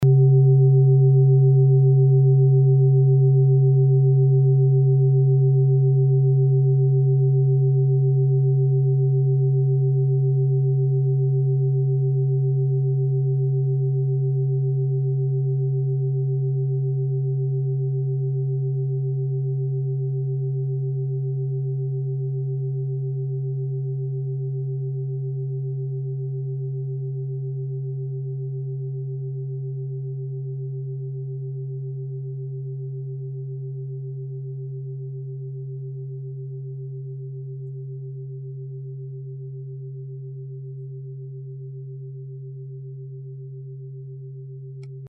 Klangschale Bengalen Nr.4
Klangschale-Gewicht: 1160g
Klangschale-Durchmesser: 21,2cm
Sie ist neu und wurde gezielt nach altem 7-Metalle-Rezept in Handarbeit gezogen und gehämmert.
(Ermittelt mit dem Filzklöppel oder Gummikernschlegel)
Der Jahreston(OM) klingt bei 136,10 Hertz und in den Oktaven ober- und unterhalb. In unserer Tonleiter ist das nahe beim "Cis".